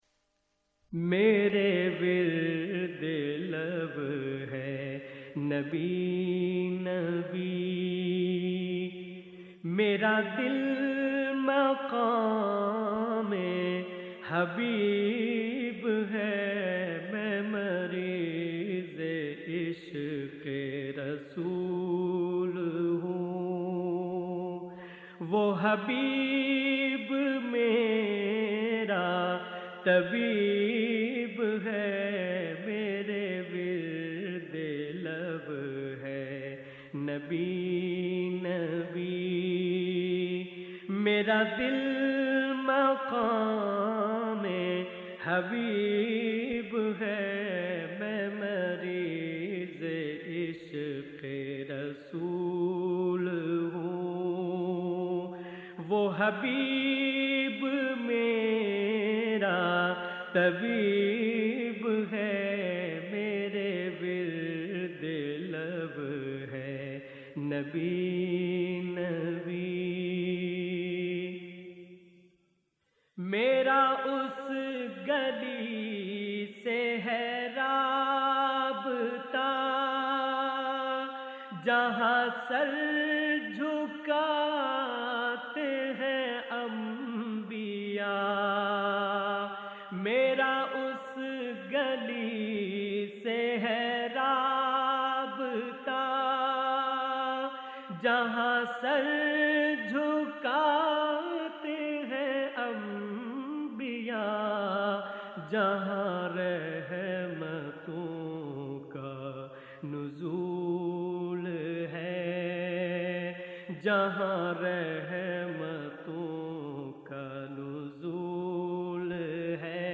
نعت رسول مقبول صلّٰی اللہ علیہ وآلہ وسلم